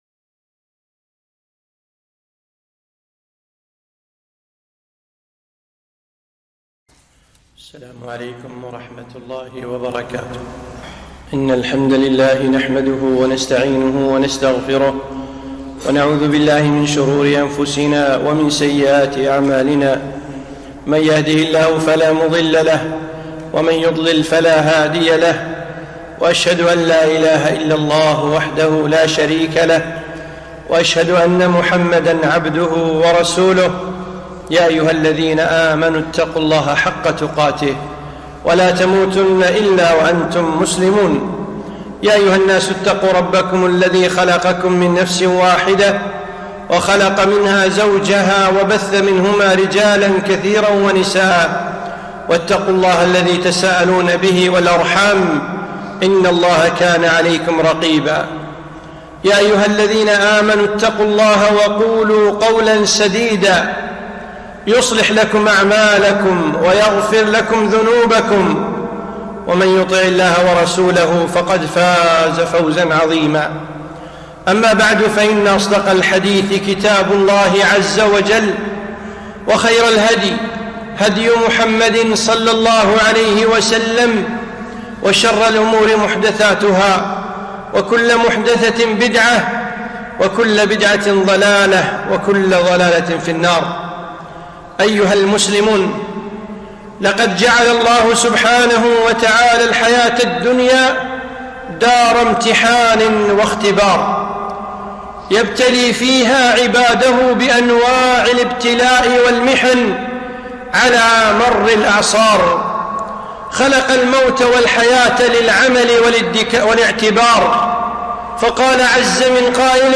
خطبة - موقف المسلم من الأمراض